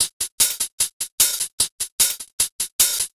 Hi Hat 03.wav